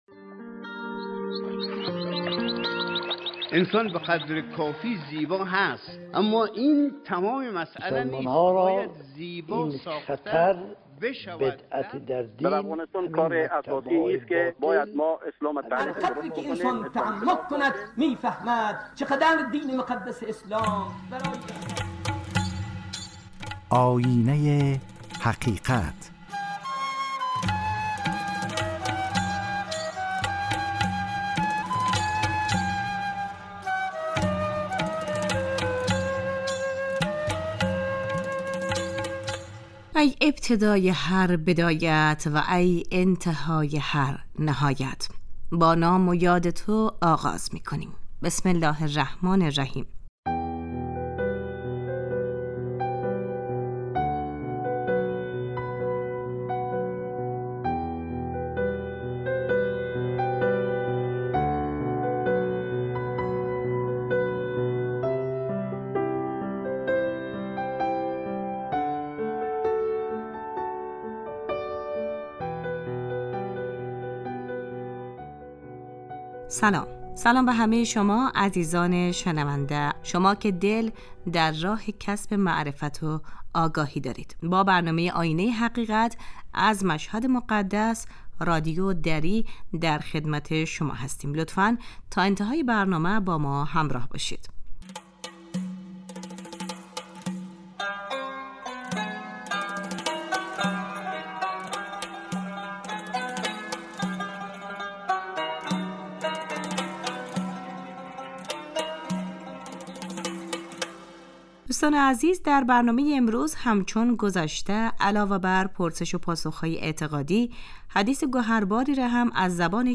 پرسش و پاسخ های اعتقادی